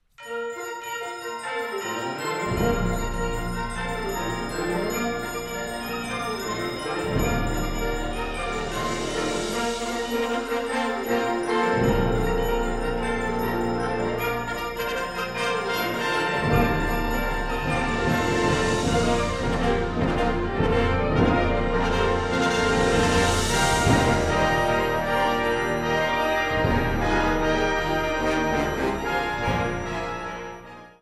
Category Concert/wind/brass band
Subcategory Original contemporary music (20th, 21st century)
Instrumentation Ha (concert/wind band)